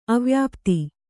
♪ avyapti